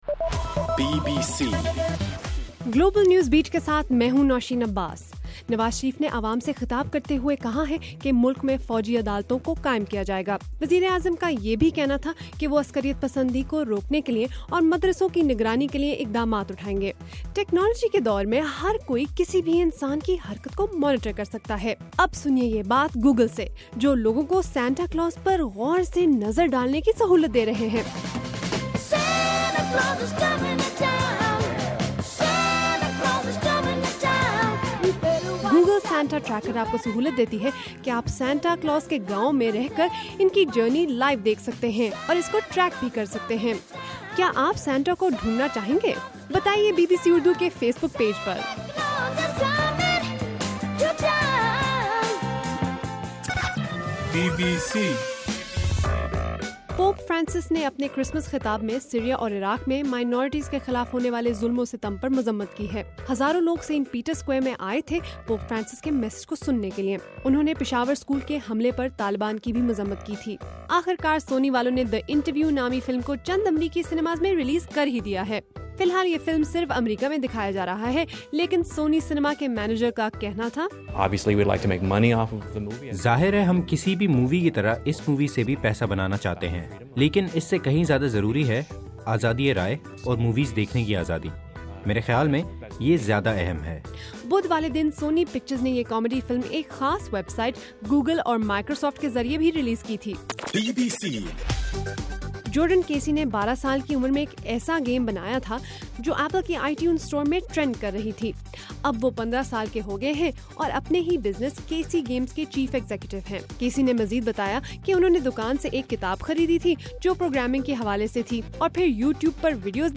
دسمبر 25: رات 10 بجے کا گلوبل نیوز بیٹ بُلیٹن